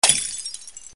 Bicchiere grande che si rompe
Suono di bicchiere grande che si rompe cadendo in terra.